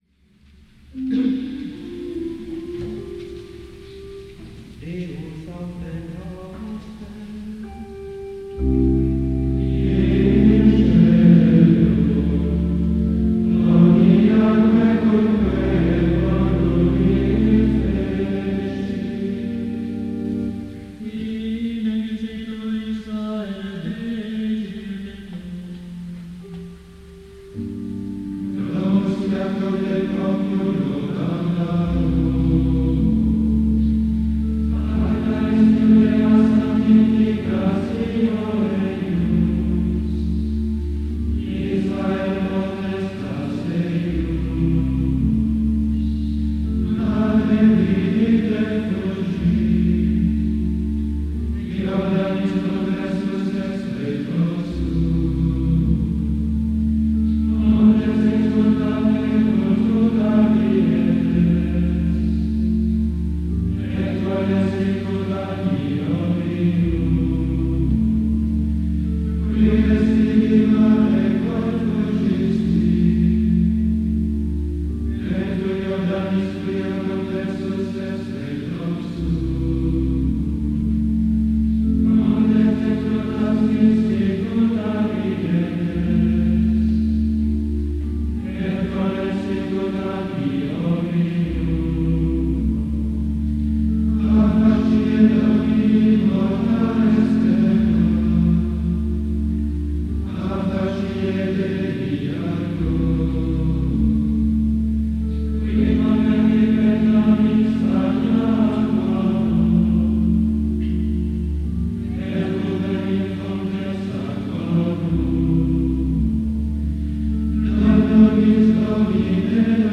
Home - Gregorian books - Propers - Saints - Hymns - Kyriale - Office - Holy Week